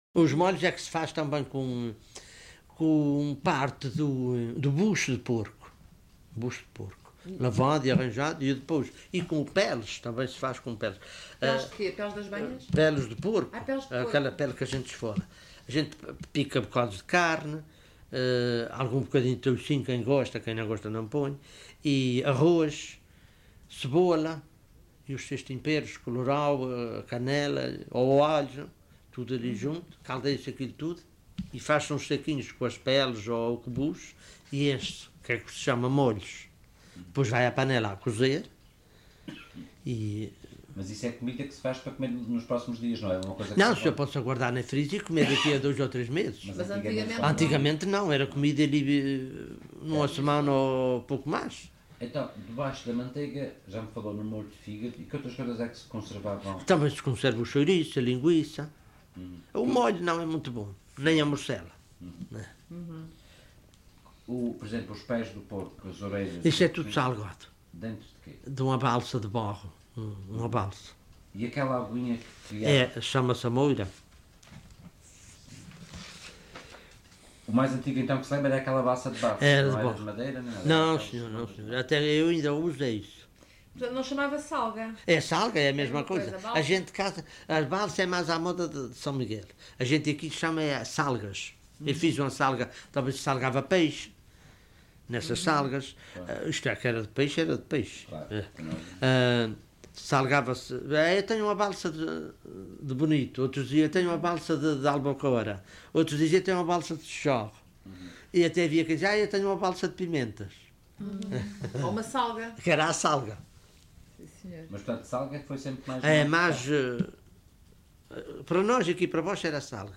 LocalidadePedras de São Pedro (Vila do Porto, Ponta Delgada)